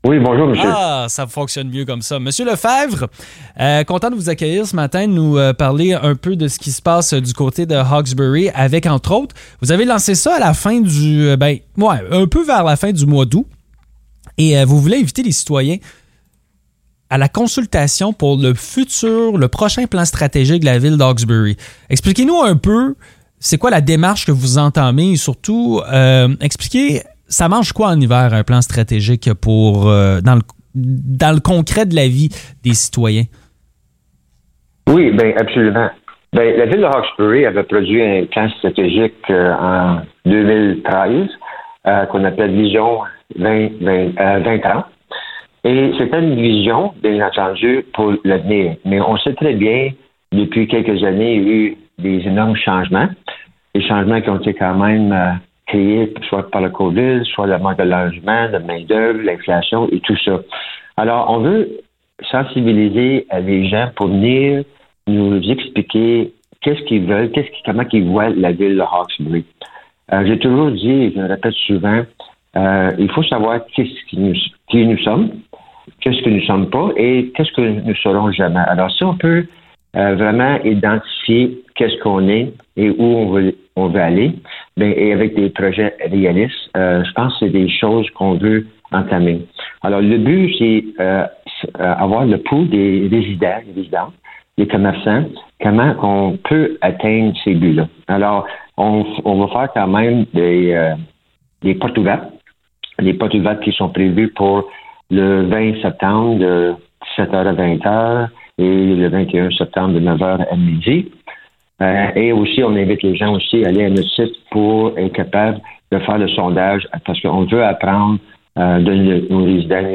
Discussion avec Robert Lefebvre, maire de Hawkesbury
Le maire de Hawkesbury, Robert Lefebvre, était avec nous ce matin.